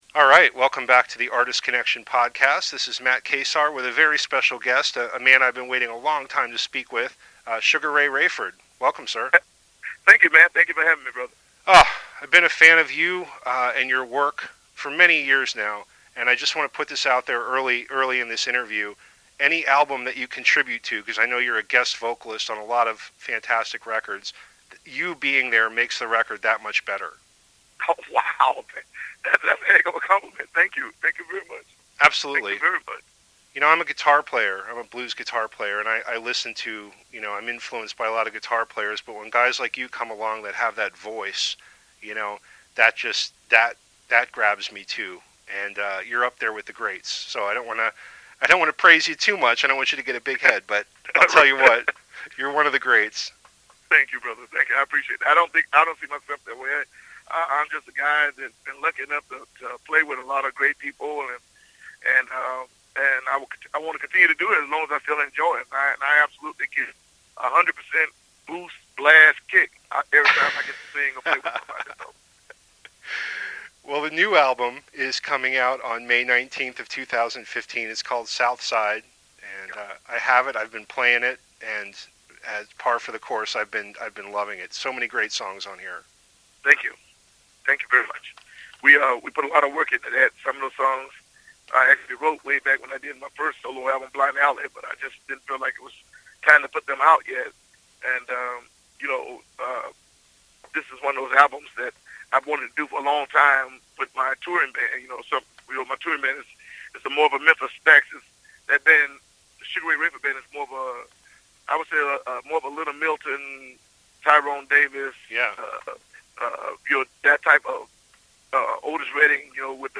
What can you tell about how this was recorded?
Three new songs are featured with this episode.